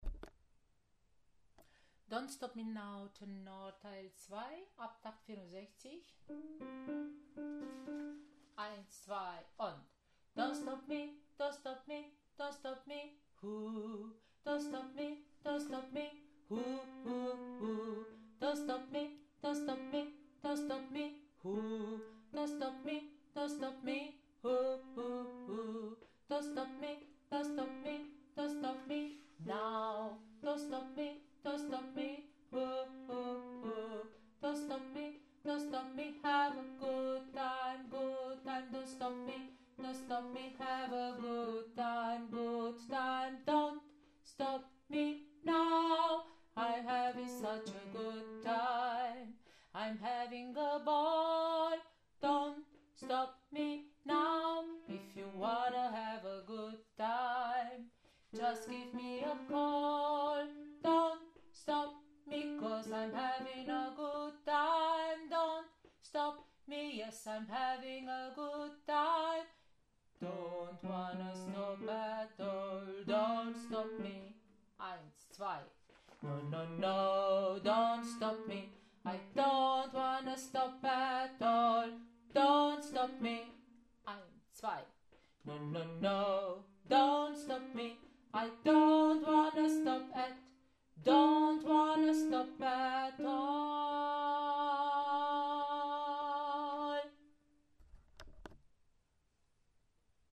04 - Tenor - ChorArt zwanzigelf - Page 4